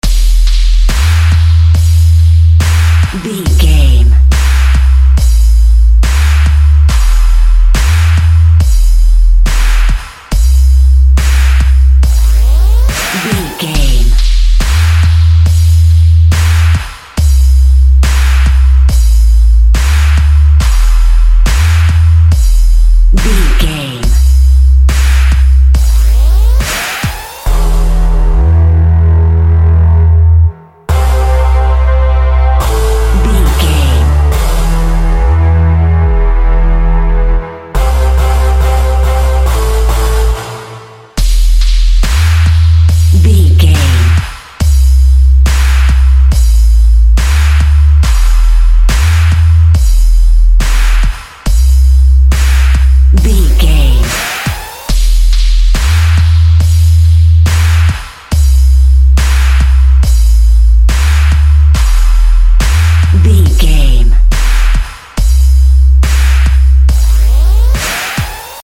Dubstep in the Machine.
Epic / Action
Fast paced
Aeolian/Minor
dark
epic
futuristic
drum machine
synthesiser
electronic
pads
industrial
glitch
synth bass